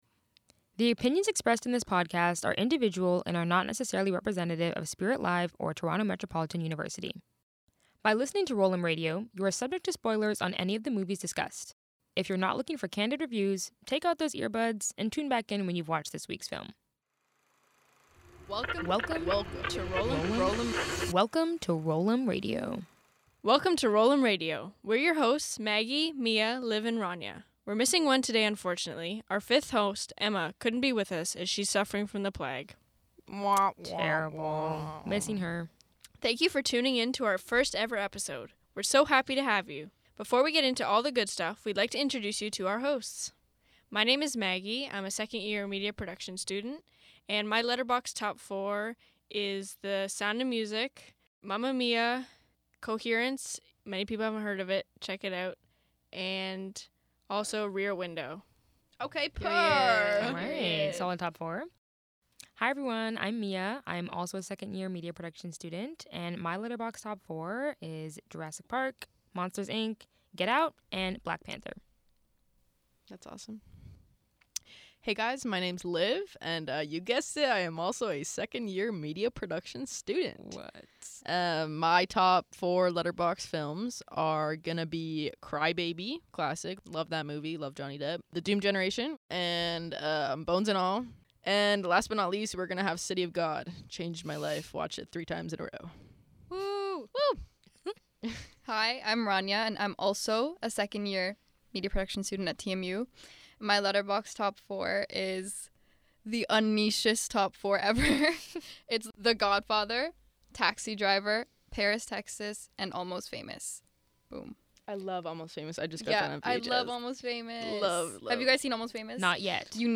One movie, five critics, and one final verdict.